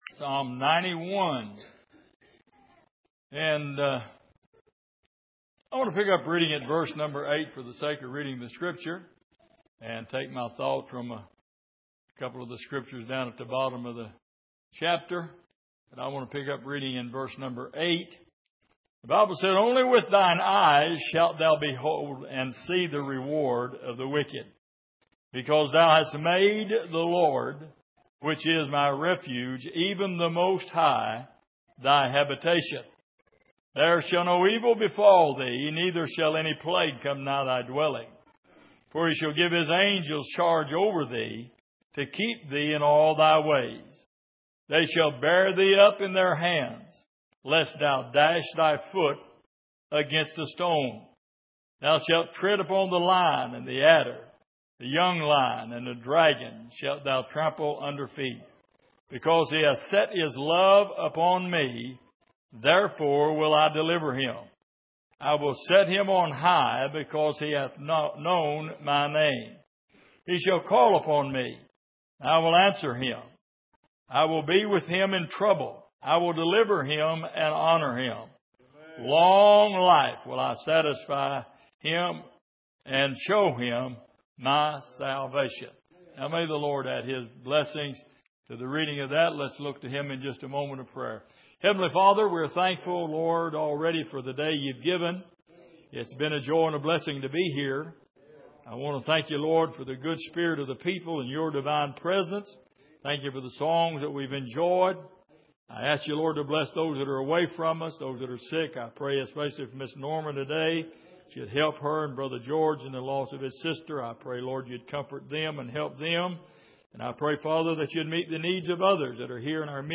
Passage: Psalm 91:8-16 Service: Sunday Morning